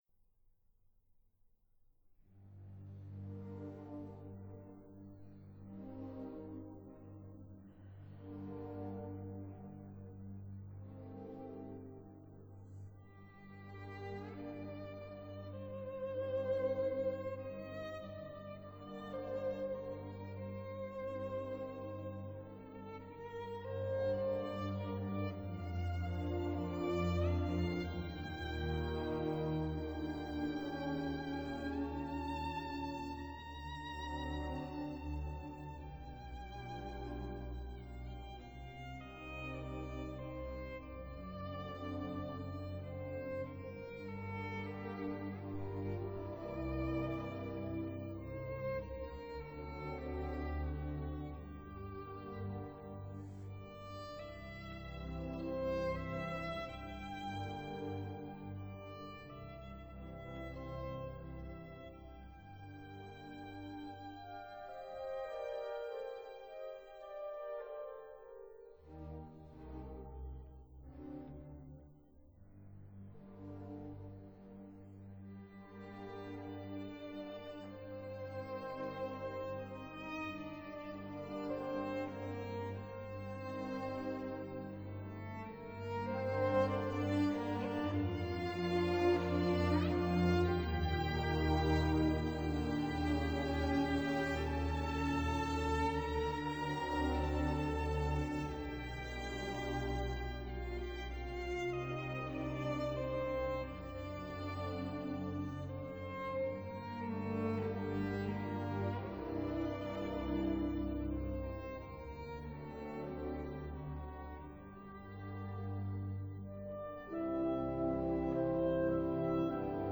conductor & solo violin